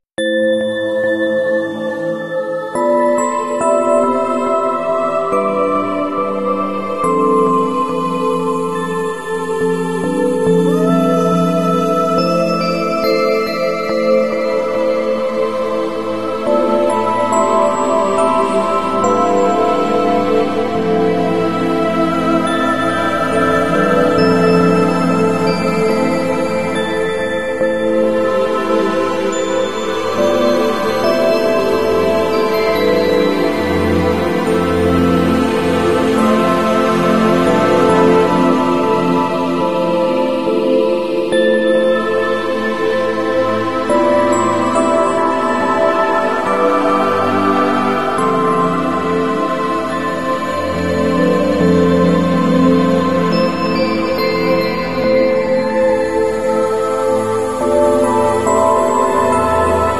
8888hz Frequency to Manifest Weath